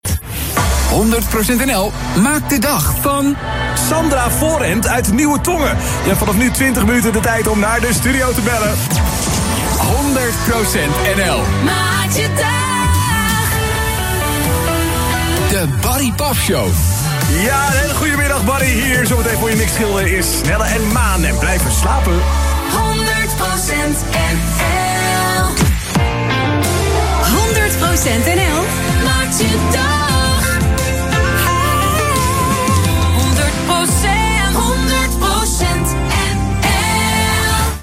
Ook is er een nieuwe uuropener.